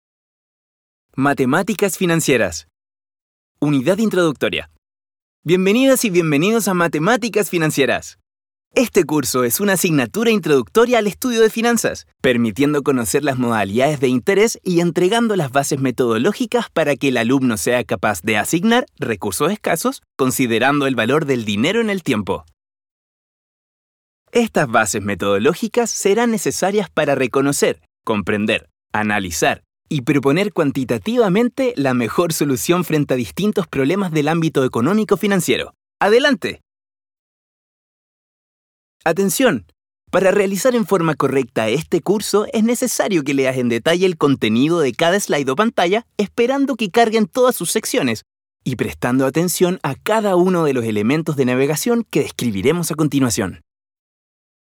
Tengo una fresca, muy versatil y original al momento de grabar.
Sprechprobe: eLearning (Muttersprache):
A young/middle spanish voice who's an Actor and very creative at work time looking for Original ideas.